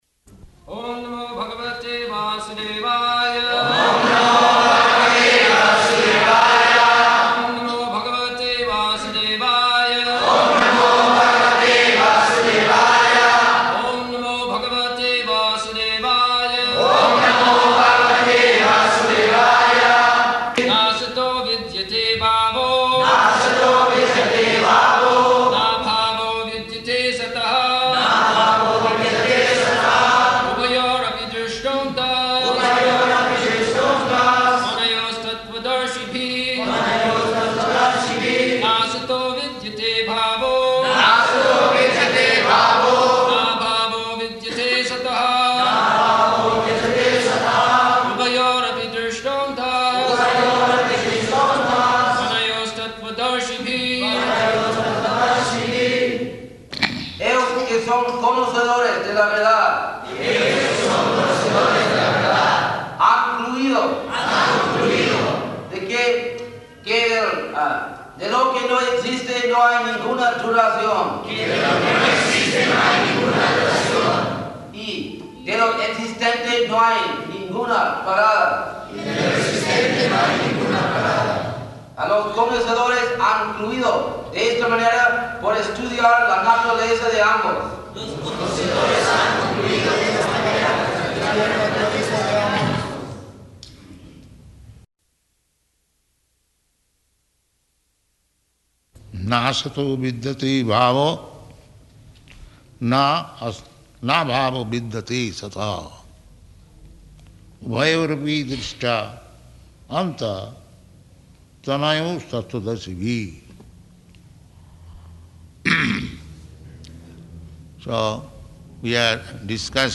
February 16th 1975 Location: Mexico City Audio file
[devotees repeat]